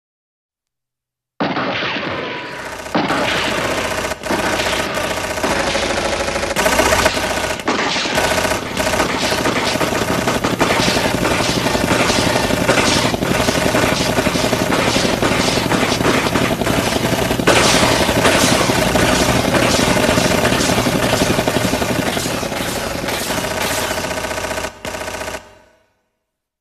ピストル 機関銃 ヘリ 銃撃戦 効果音